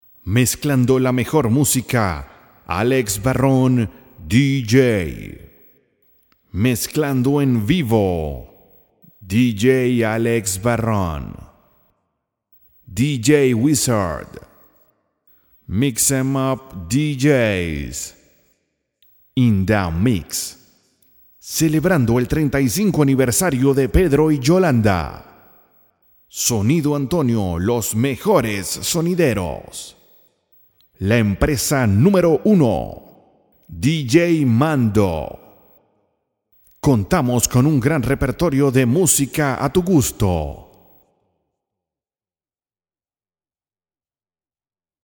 Voz de excelentes rangos medios y graves.
spanisch Südamerika
Kein Dialekt